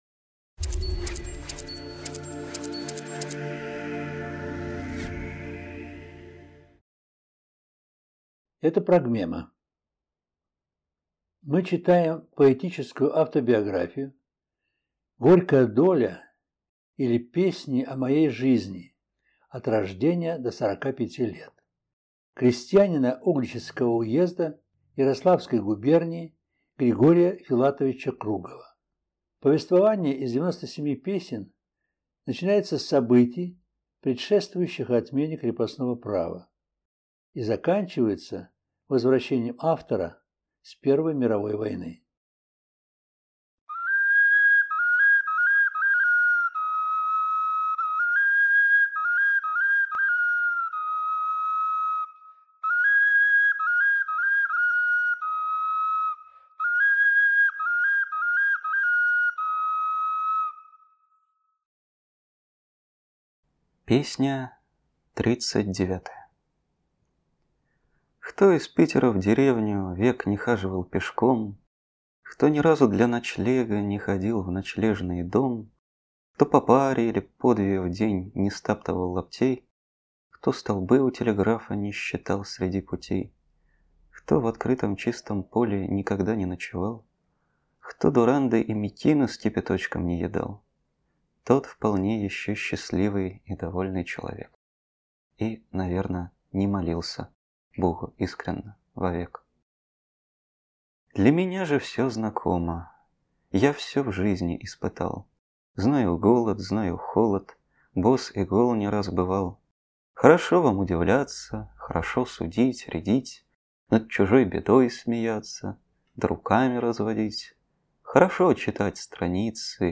В записи использованы звучание глиняных Скопинских, Суджанских, Чернышенских игрушек-окарин